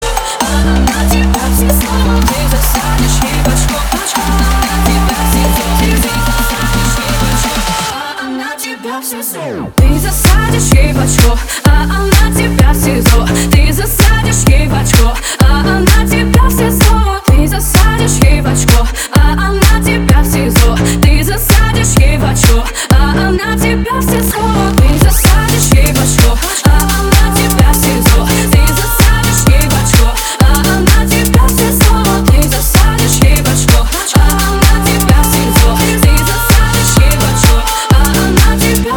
• Качество: 320, Stereo
веселые